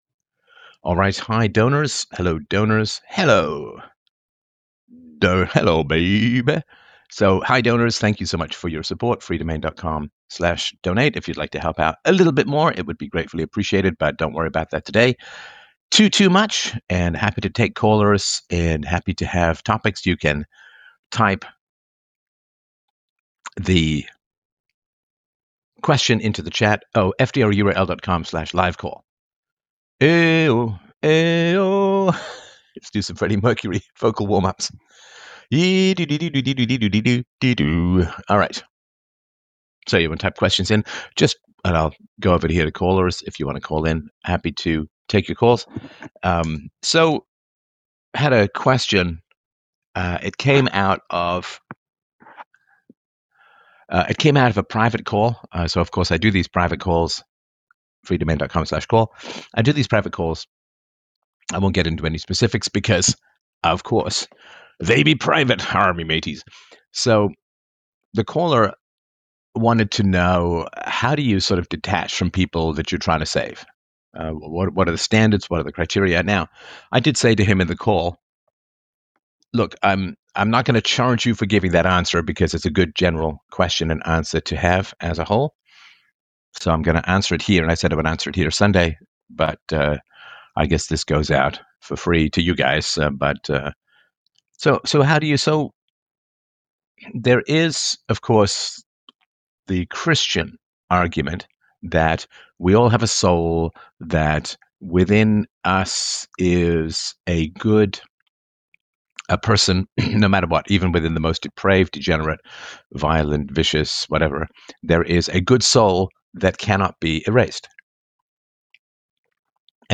6057 When to Give Up! Premium Livestream
Sunday Morning LIVE - Donors Show - PLUS a chapter from my new novel!The chapter reading starts at 49:17.This lecture explores the complexities of interpersonal…